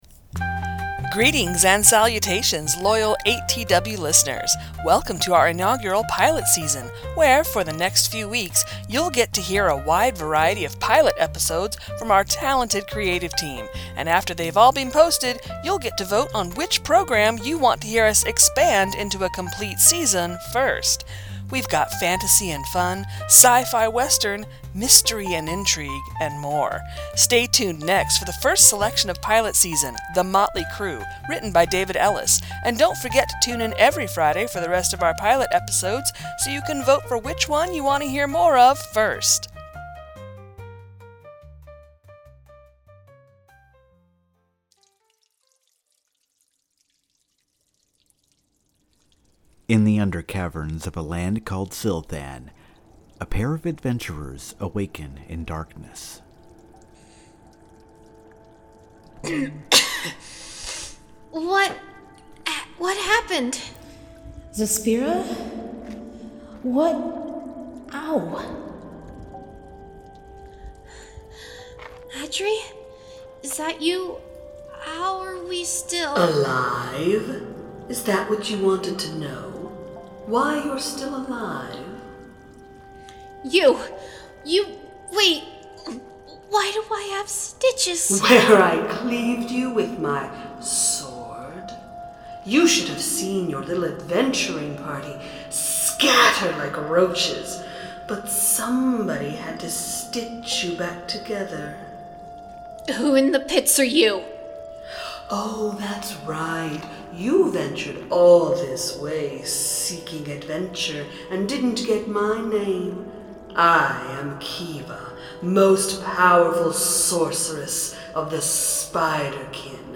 best audio drama